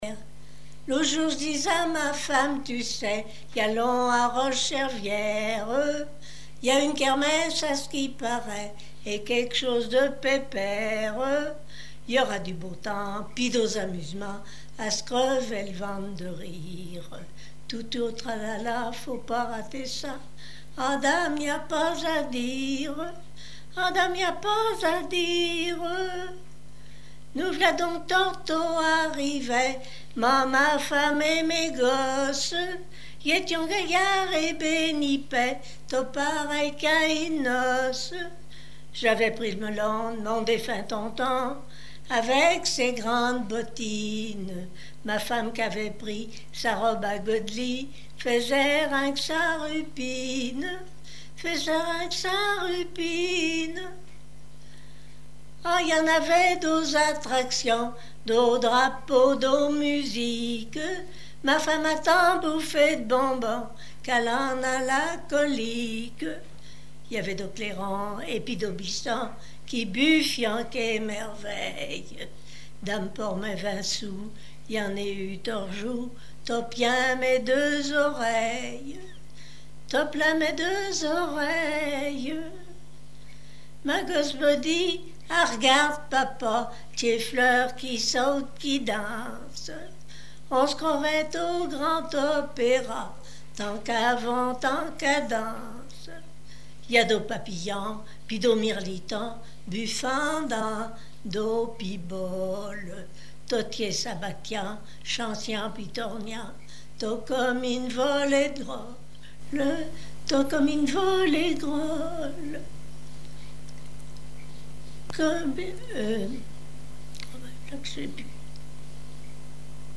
Patois local
Pièce musicale inédite